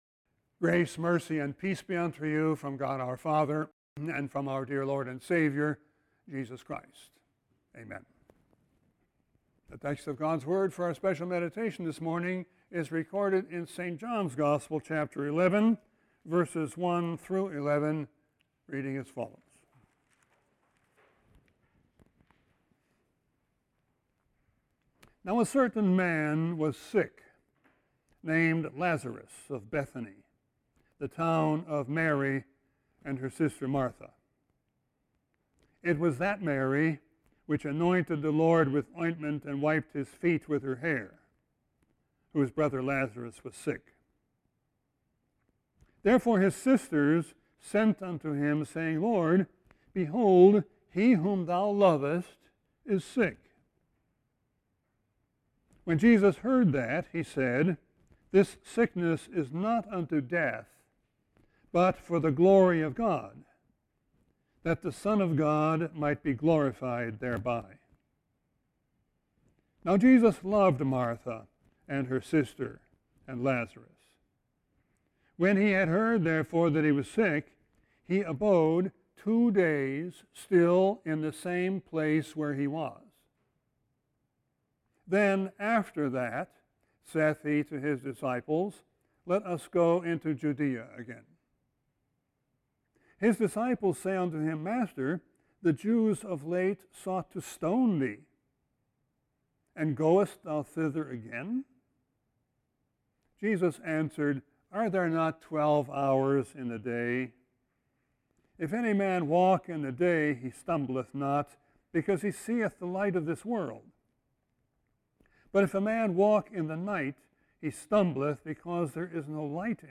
Sermon 9-12-21.mp3